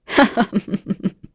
Glenwys-Vox_Happy3.wav